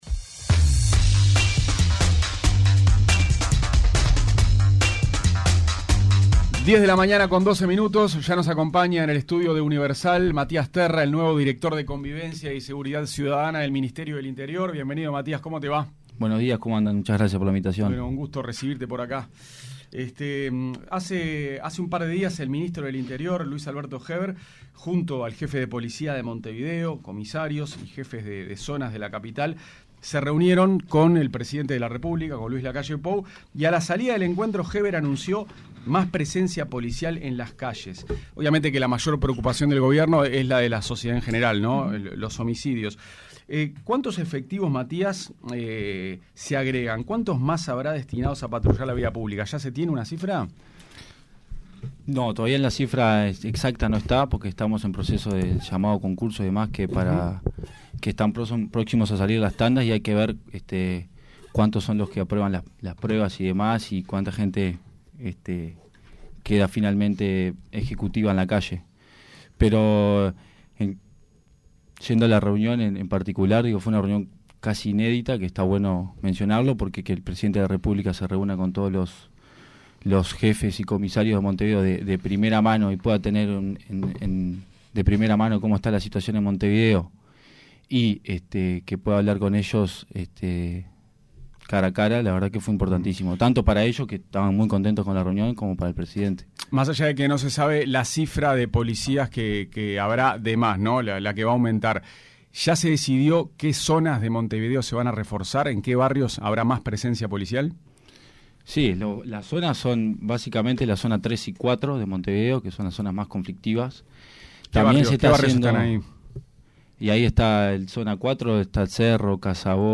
El director de Convivencia y Seguridad Ciudadana del Ministerio del Interior, Matías Terra, dijo en entrevista en Punto de Encuentro en 970 Universal que la decisión de mantener «un perfil bajo» dentro de la dirección fue de «acuerdo mutuo» con el ministro Luis Alberto Heber y señaló que hará más hincapié en la convivencia que en la seguridad ciudadana. Detalló que no participará de los operativos policiales ni tampoco en la cárceles.